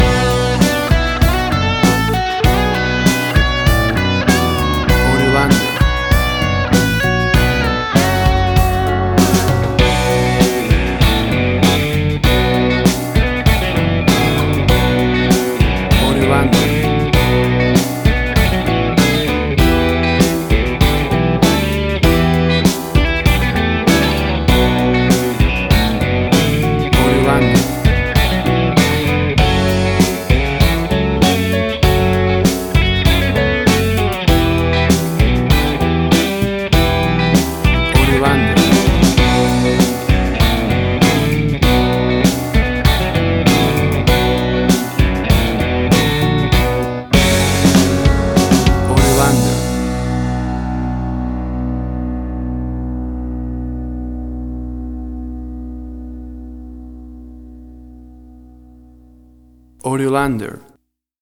Tempo (BPM): 98